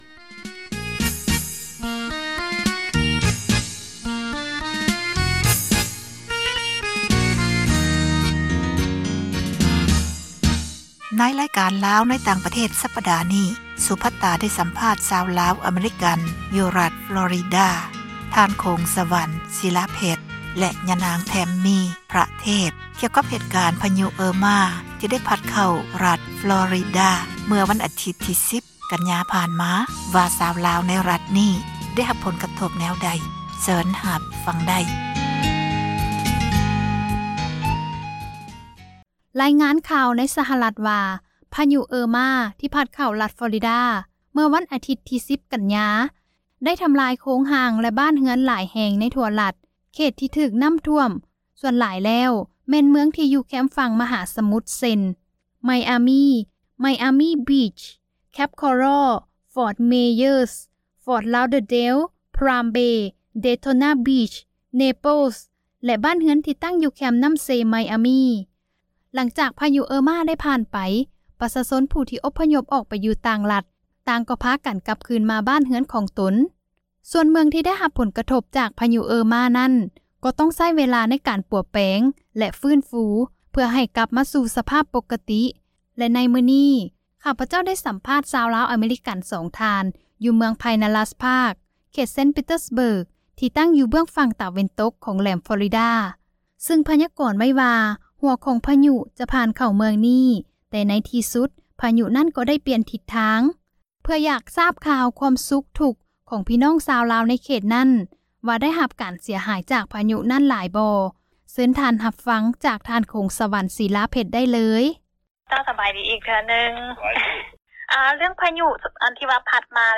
ສໍາພາດຊາວລາວຫຼັງພະຍຸ Irma